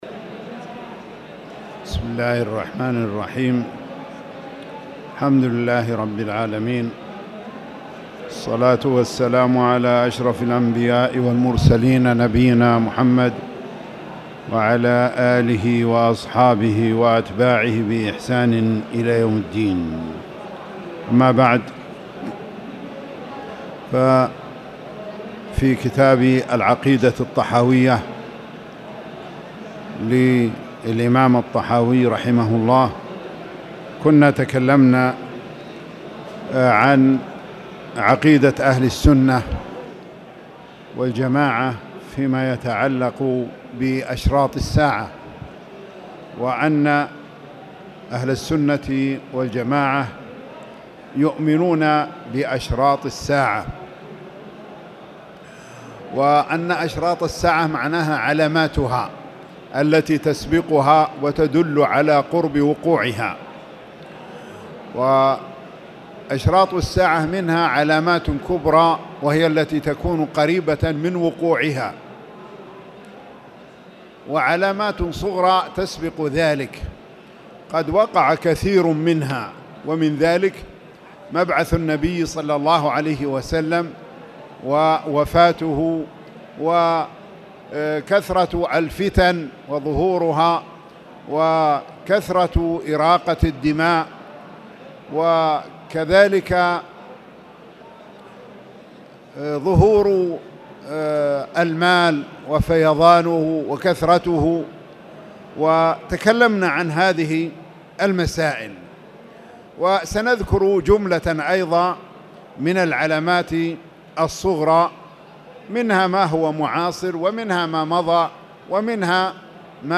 تاريخ النشر ٢ جمادى الأولى ١٤٣٨ هـ المكان: المسجد الحرام الشيخ